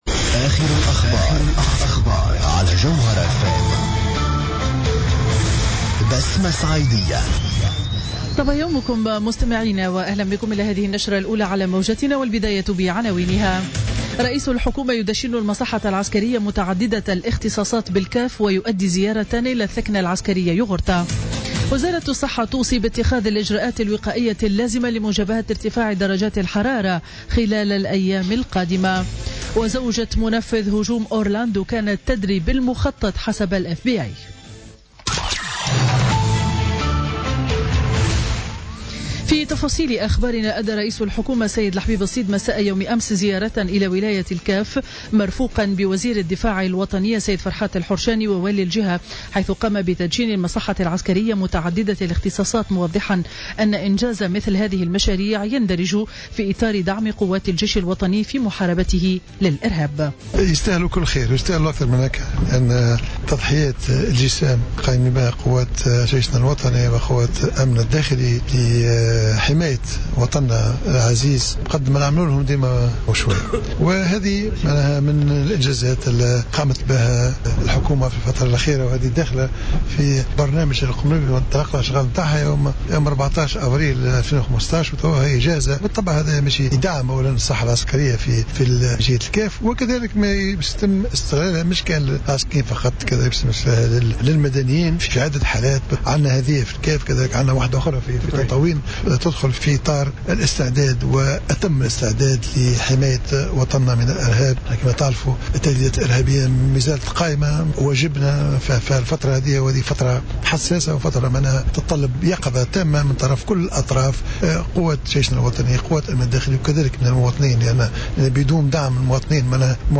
نشرة أخبار السابعة صباحا ليوم الأربعاء 15 جوان 2016